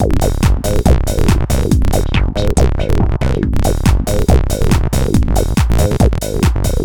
标签： 140 bpm Techno Loops Groove Loops 1.15 MB wav Key : Unknown